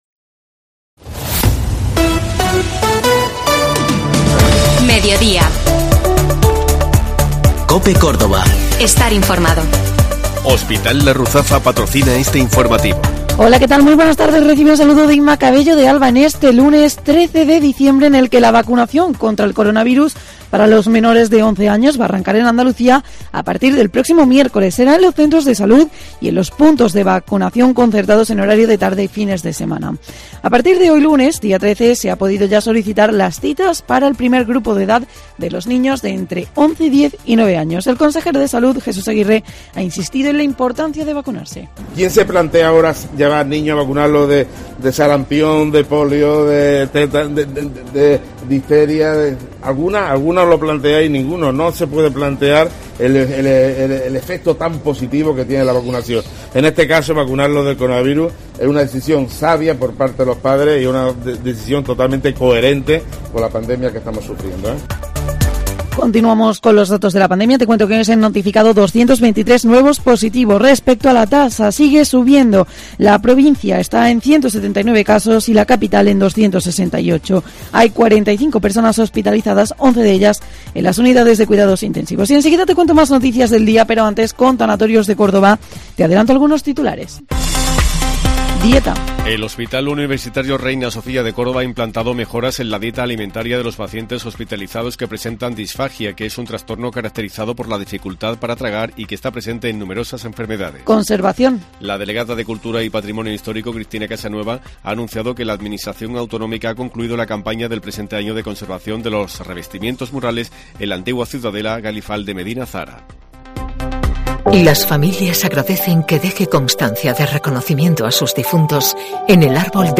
LA ACTUALIDAD CADA DÍA